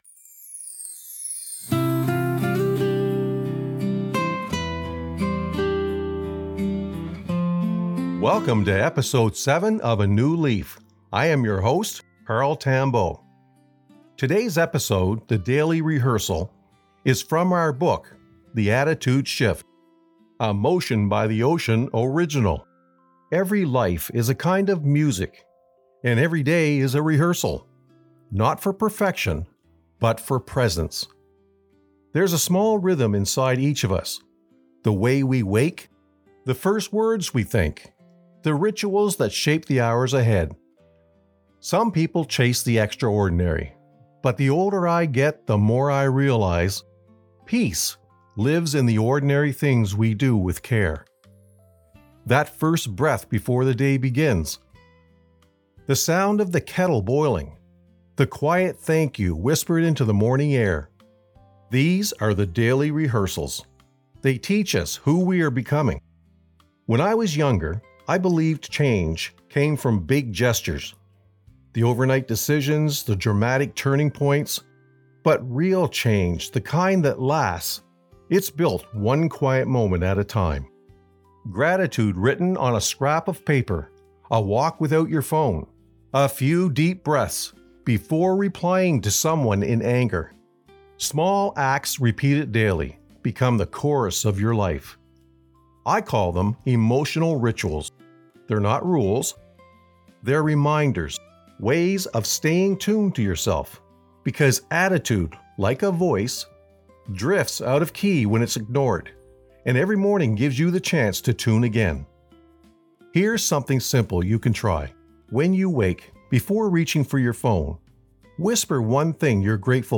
Through calm narration and ocean-inspired imagery, this episode reminds us that growth doesn’t happen all at once.
A soft, grounding companion for anyone seeking consistency, intention, and a deeper connection to the rhythm of their own life.